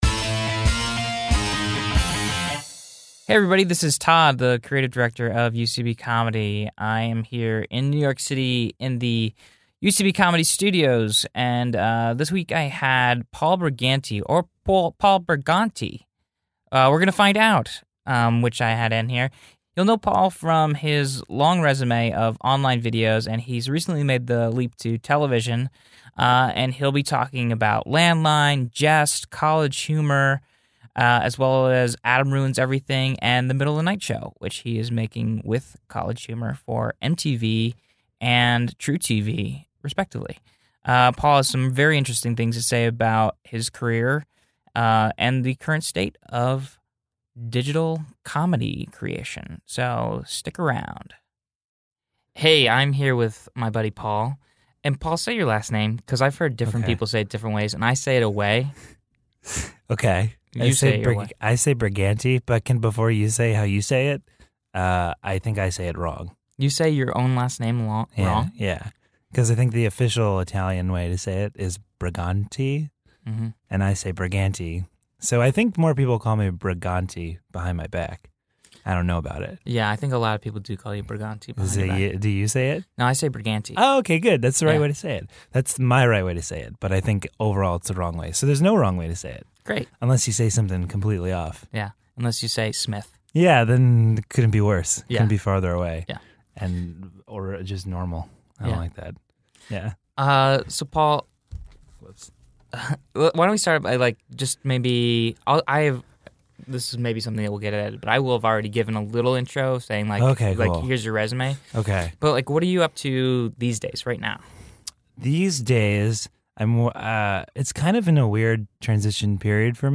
Recorded at UCB Comedy studios in NYC.